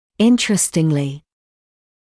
[‘ɪntrəstɪŋlɪ][‘интрэстинли]интересно, занимательно, любопытно